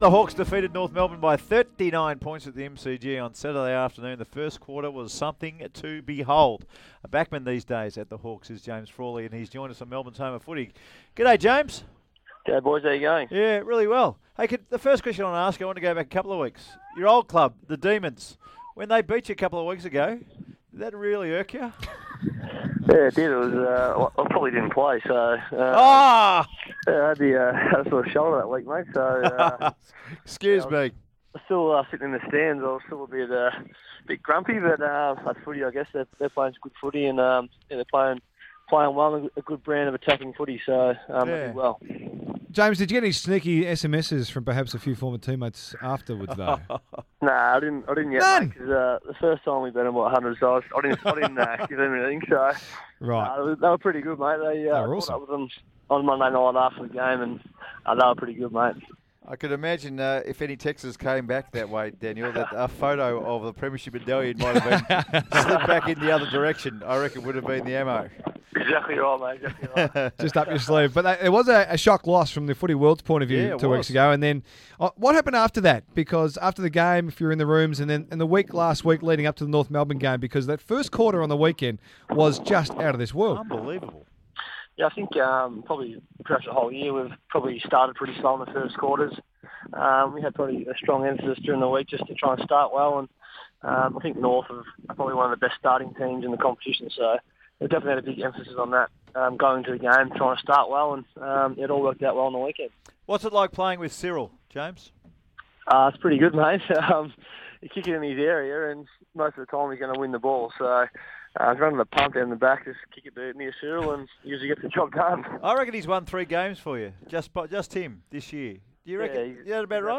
James Frawley Interview